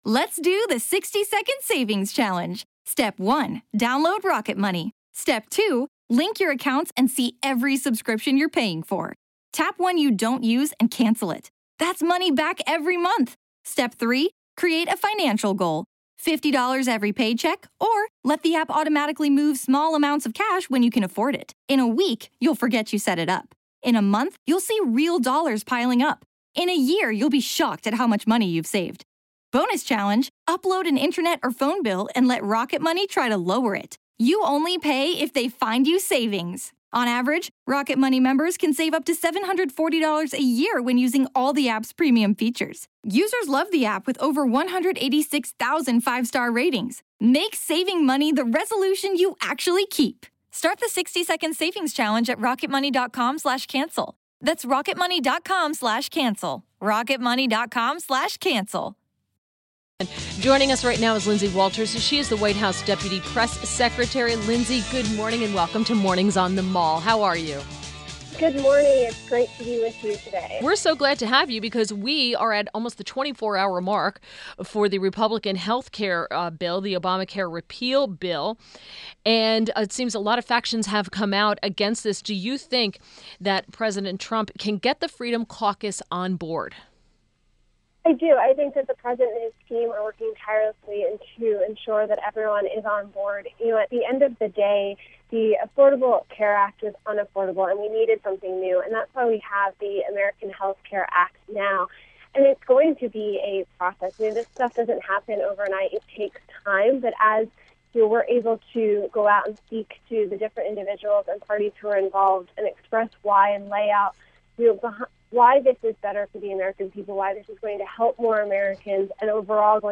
INTERVIEW – LINDSAY WALTERS – WHITE HOUSE DEPUTY PRESS SECRETARY — explained the proposed GOP healthcare bill and its benefits.